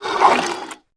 Heroes3_-_Crimson_Couatl_-_AttackSound.ogg